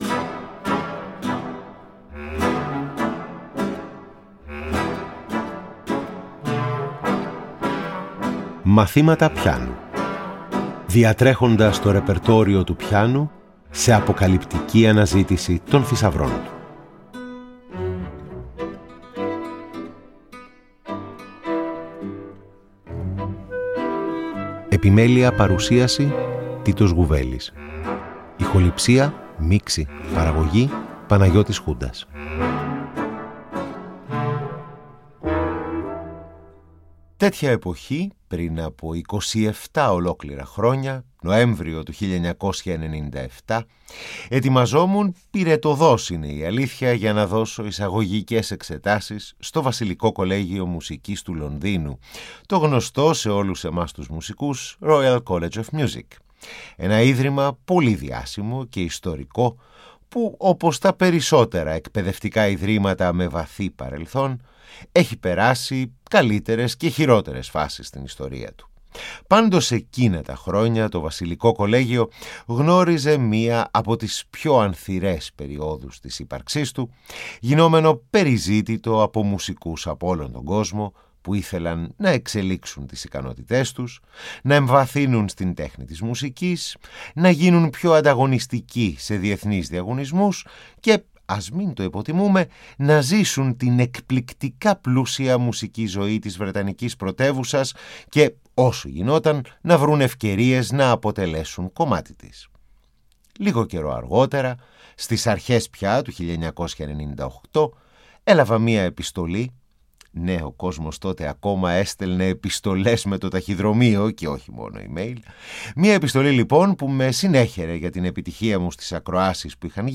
Ακούγονται σπάνιες ηχογραφήσεις των νεανικών του χρόνων
Εργα για Πιανο